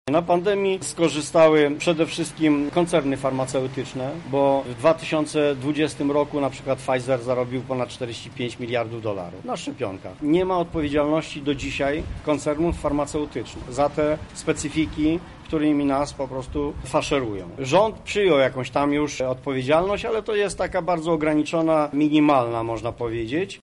• mówi Stanisław Brzozowski, Radny Rady Miasta.